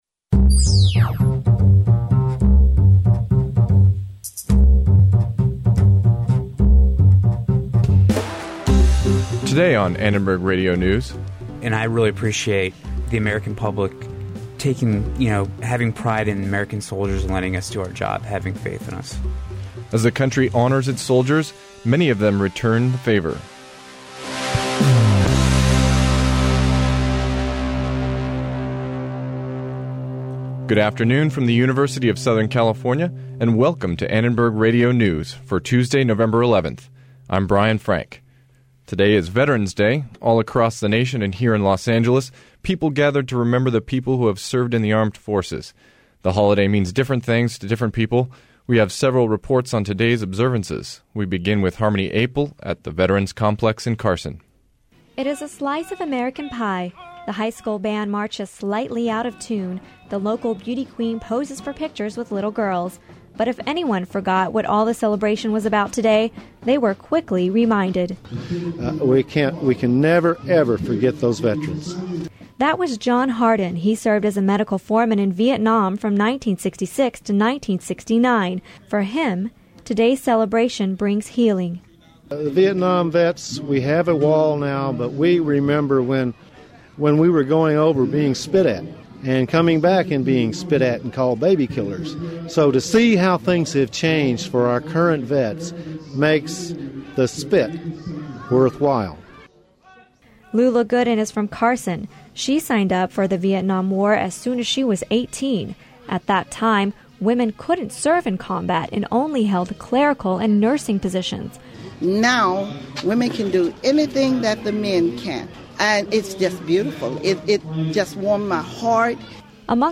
Today is Veterans Day and communities across L.A. County honor the men and women who served in the armed forces. We visit a steak luncheon for veterans living on Skid Row, and we also interview two Iraq War vetson their homecoming experience.